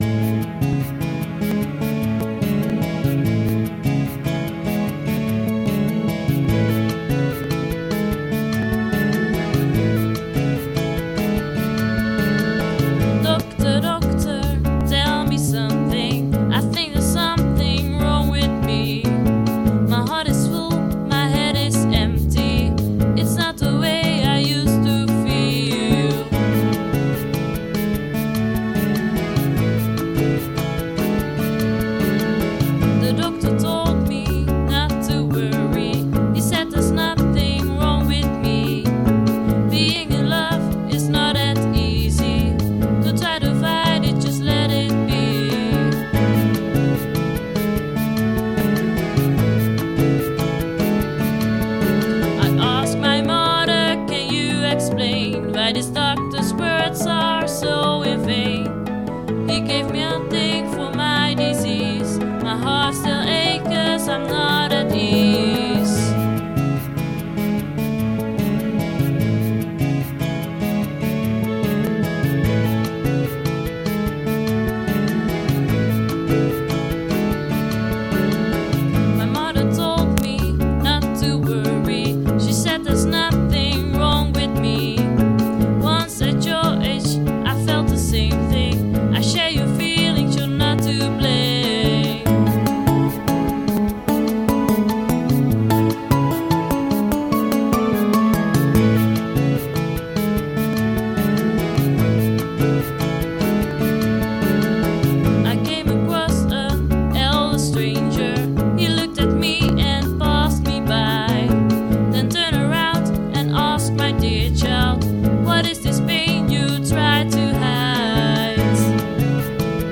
Engelstalig, jazzpop)